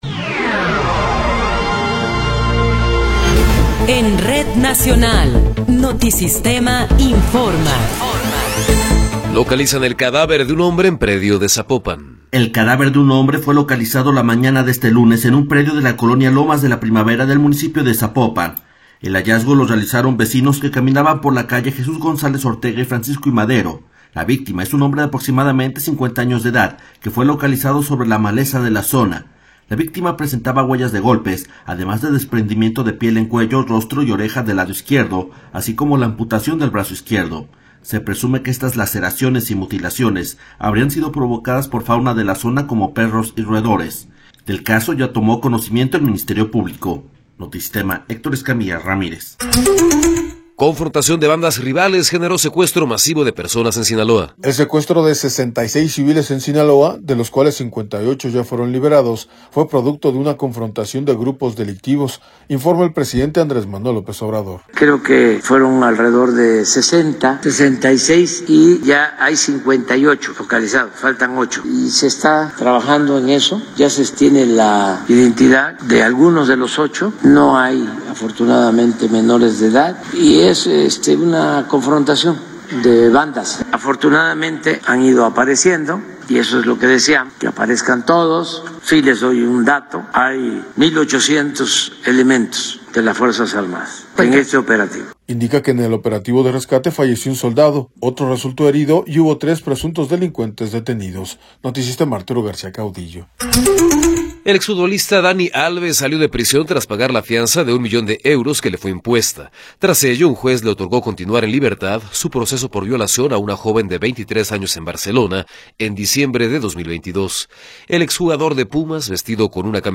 Noticiero 11 hrs. – 25 de Marzo de 2024
Resumen informativo Notisistema, la mejor y más completa información cada hora en la hora.